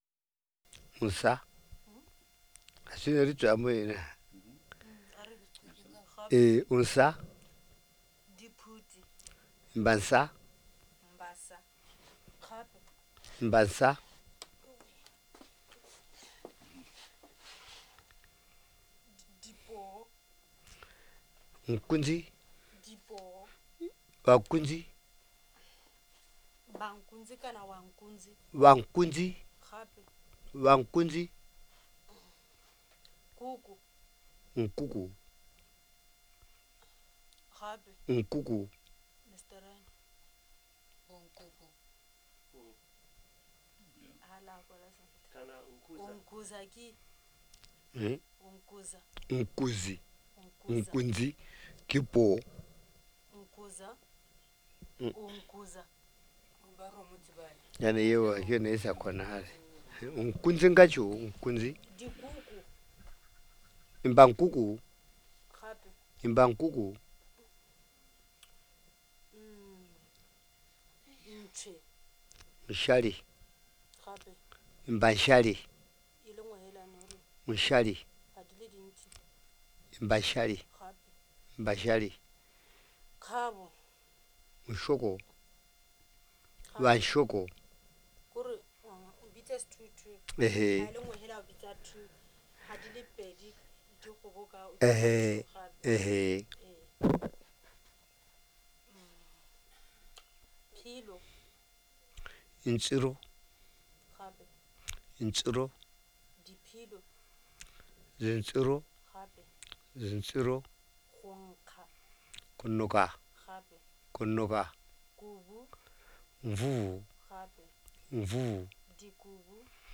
Word List Word List Entries Speaker(s) Audio Filename WAV MP3 Scanned Word List (JPG) JPG 2Scanned Word List (TIF) TIF 2Recording Details
male